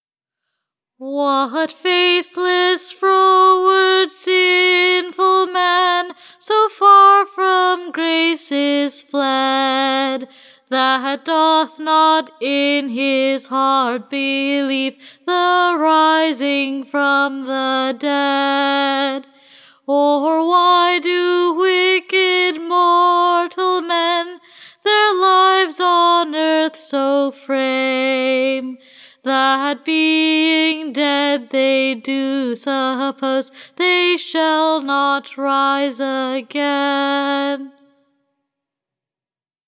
Recording of stanza 1 of “Resurrection” ballad